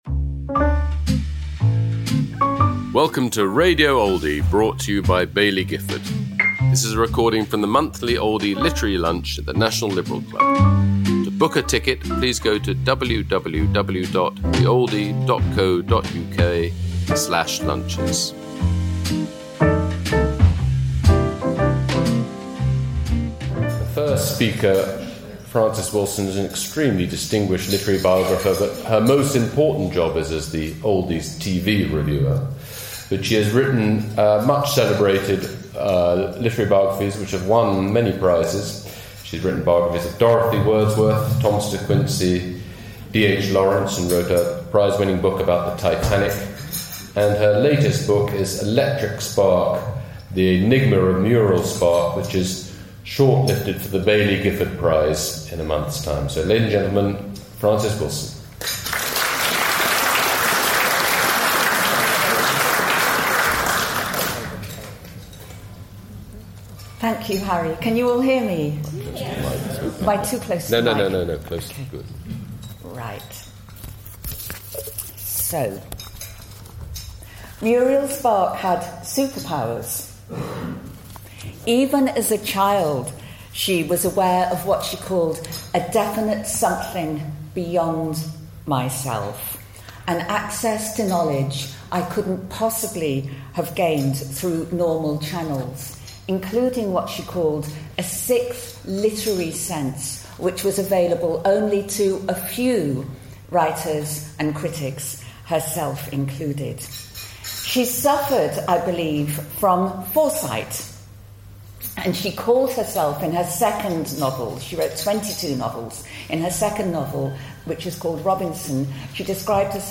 Frances Wilson speaking about her new book, Electric Spark: The Enigma of Muriel Spark, at the Oldie Literary Lunch, held at London’s National Liberal Club, on October 7th 2025.